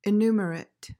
PRONUNCIATION:
(i-NOO/NYOO-muhr-it)